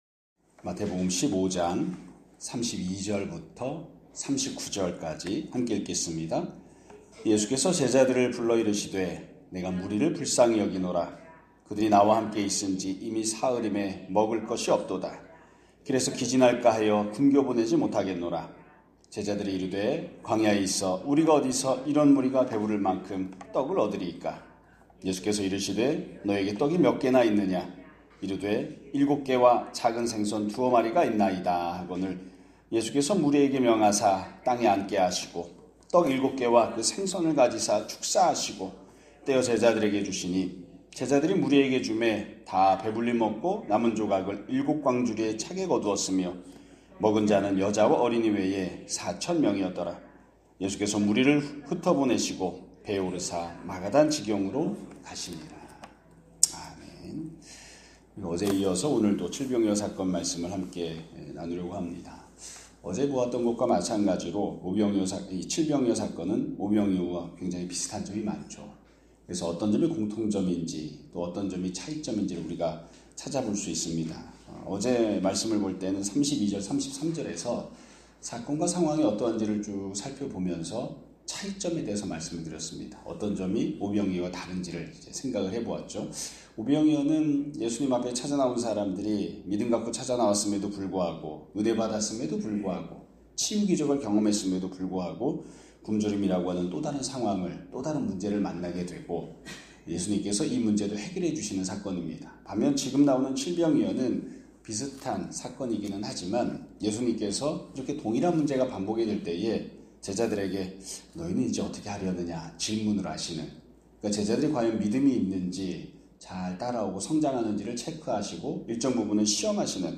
2025년 11월 12일 (수요일) <아침예배> 설교입니다.